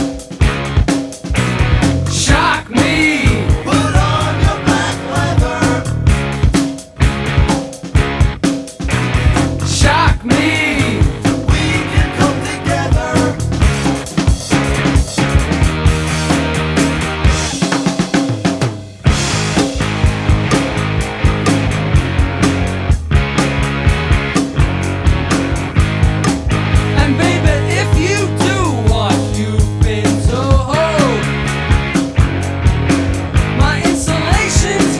Жанр: Рок
Hard Rock